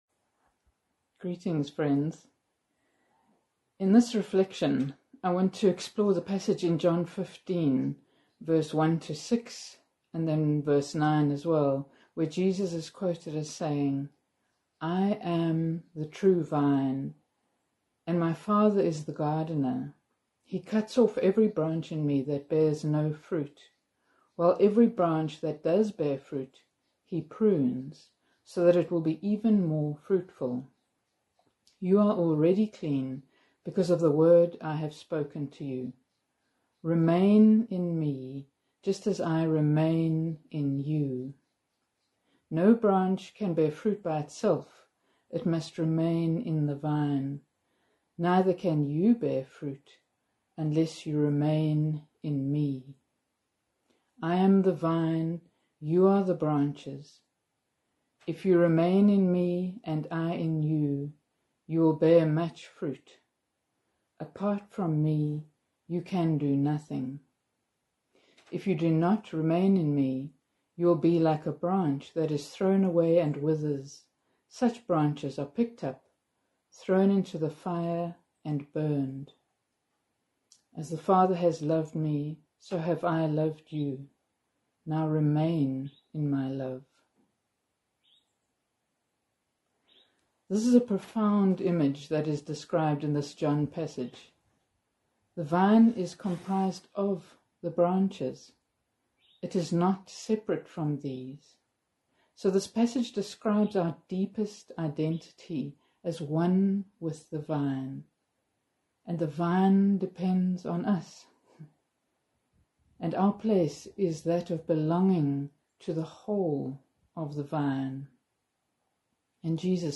After some time of quiet reflection on this scripture passage you can listen to the following talk on the theme of Remain in Me.